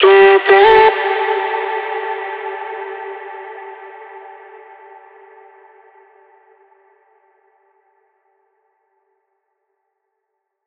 VR_vox_hit_getthere_Dmin.wav